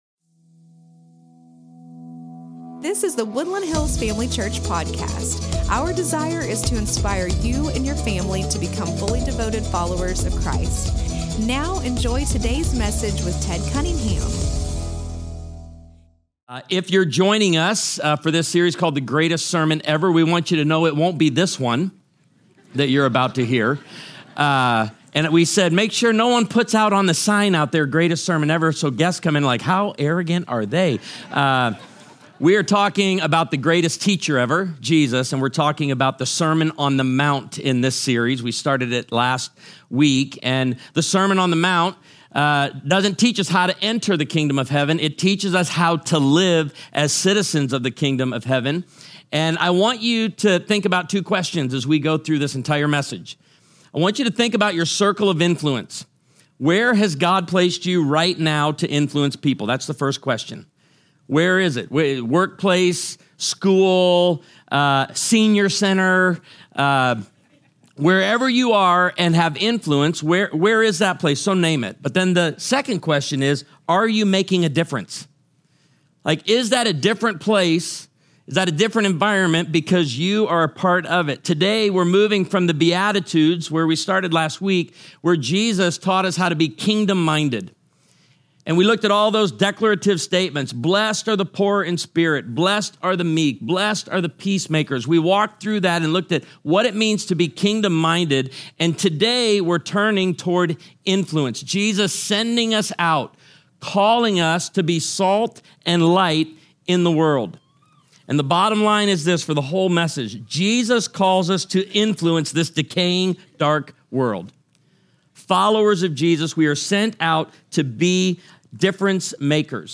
The Greatest Sermon Ever (Part 2)